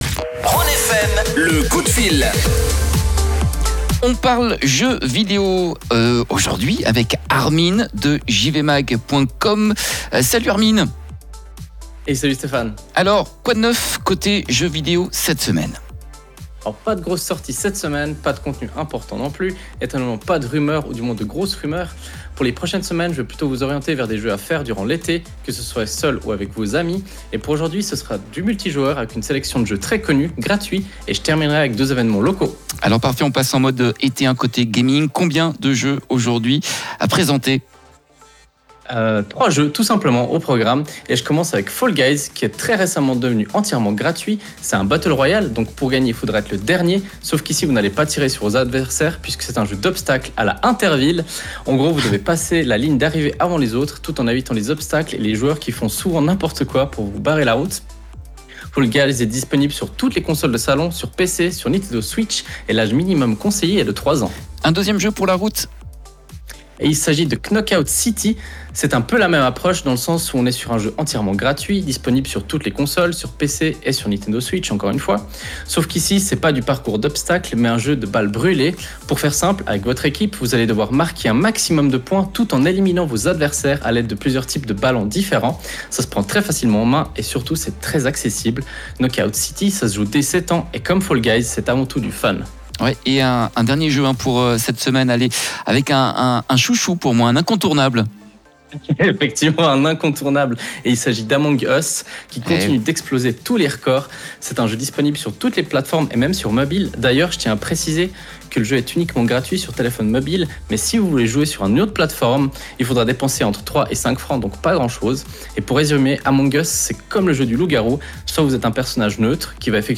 Aujourd’hui on se concentre sur des titres que pouvez emporter partout avec vous et qui ne dépassent pas les 30.- CHF. De GRIS à Hades, le live est à réécouter juste en dessus.